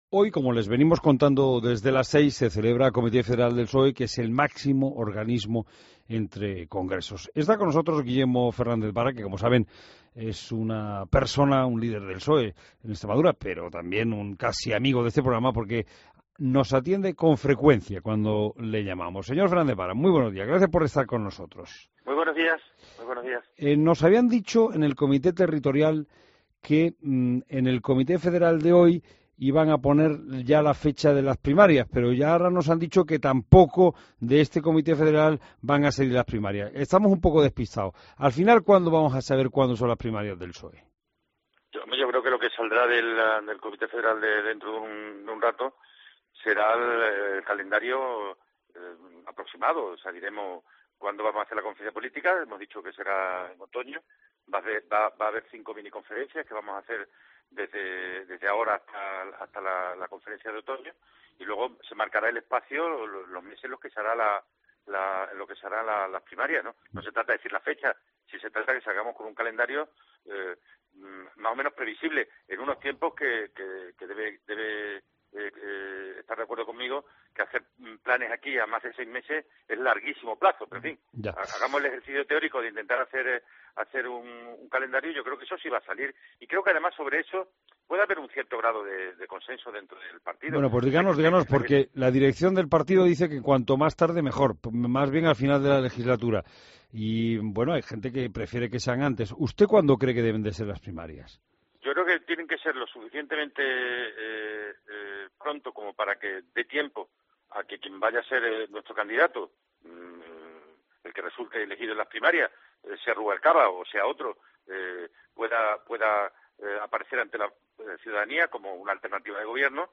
Escuha la entrevista a Fernández Vara en La Mañana Fin de Semana